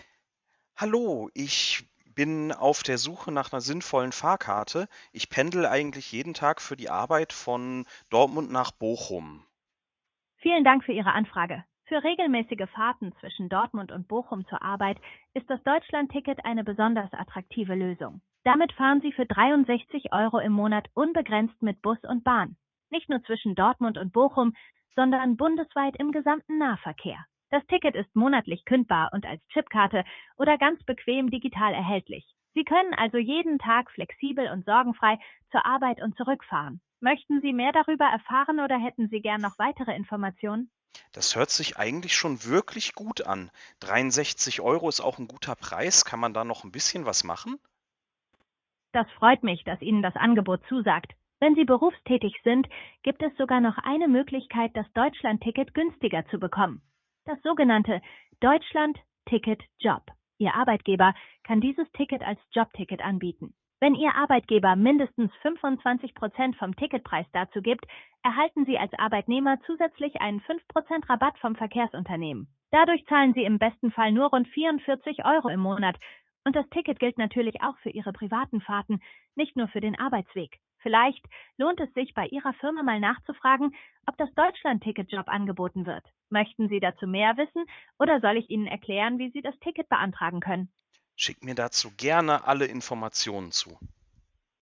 Für jeden Fall die passende KI mit der passenden Stimme:
KI-OePNV.mp3